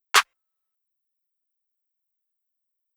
Clap + Snare.wav